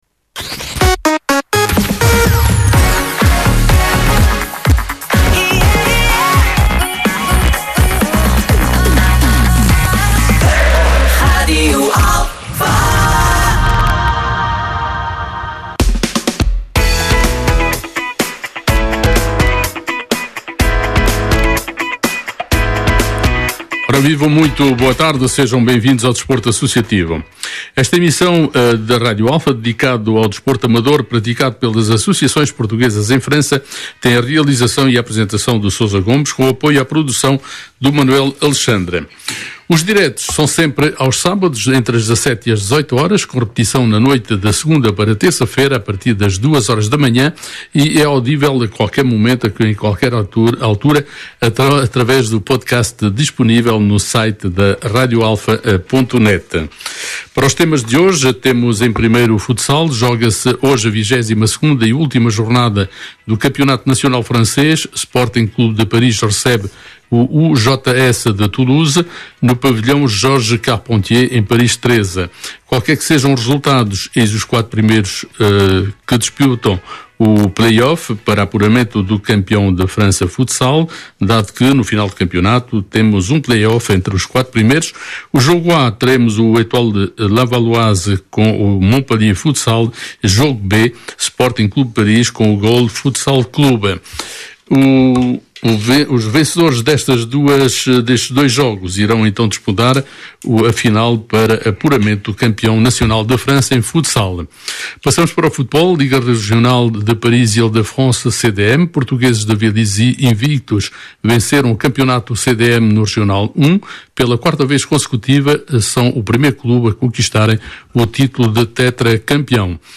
Emissão de 31 de Maio de 2025 -- Em Estúdio Portugueses de Vélizy